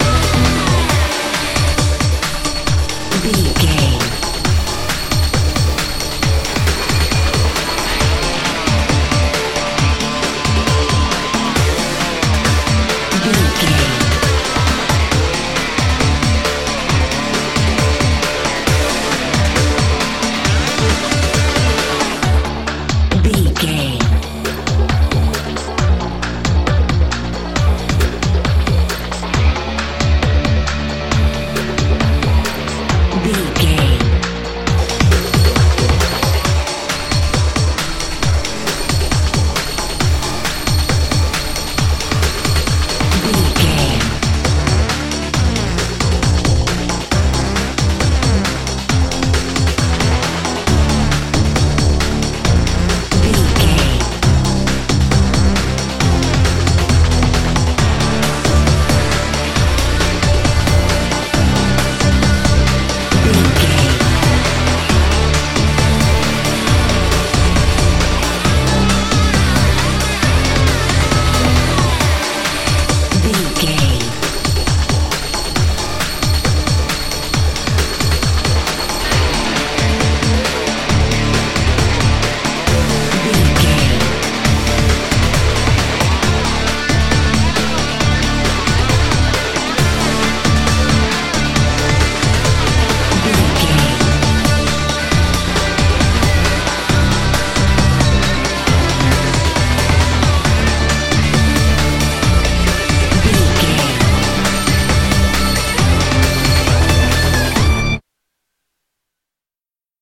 Aeolian/Minor
hard rock
metal
lead guitar
bass
drums
aggressive
energetic
intense
nu metal
alternative metal